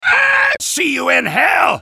soldier_paincrticialdeath03.mp3